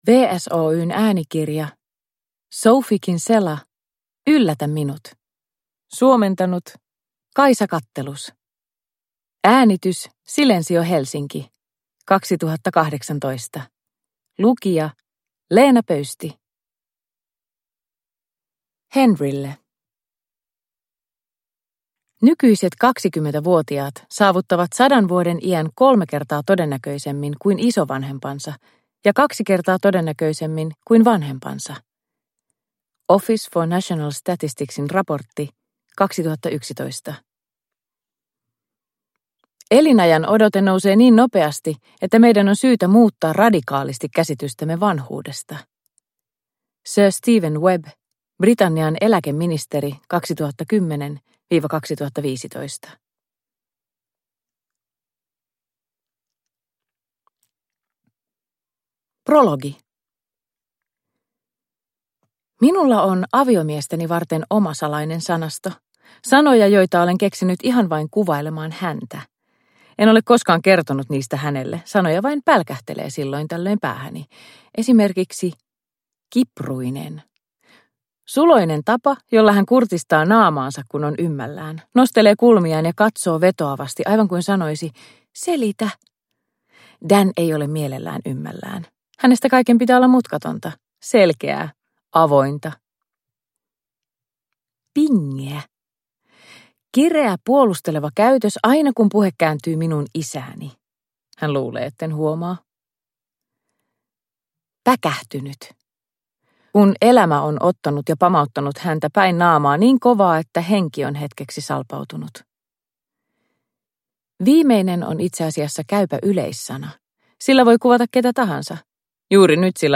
Yllätä minut – Ljudbok